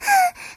moan6.ogg